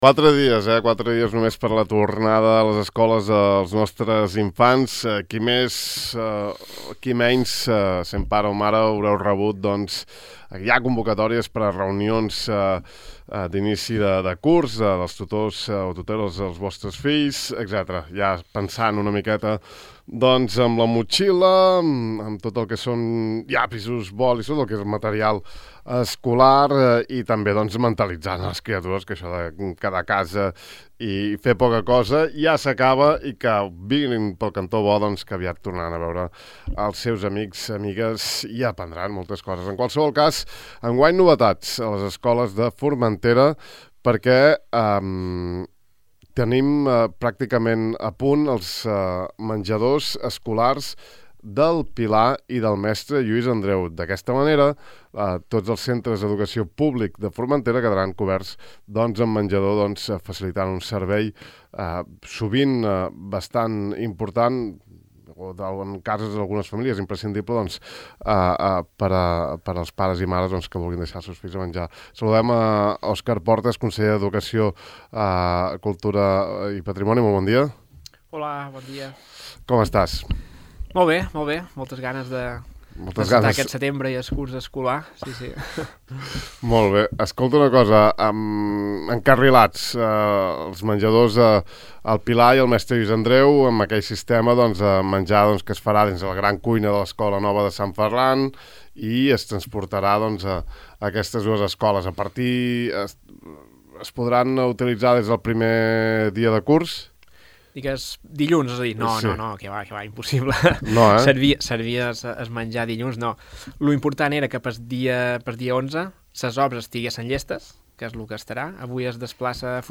El nou servei de menjador per a alumnes que s’ha d’implementar enguany a les escoles Mestre Lluís Andreu i del Pilar, es preveu que entri en funcionament, si no hi ha cap impediment, durant la segona setmana del curs que s’inicia dilluns 11, d’aquí a quatre dies, segons ha avançat en entrevista a Ràdio el titular de la cartera d’educació del Consell, Òscar Portas.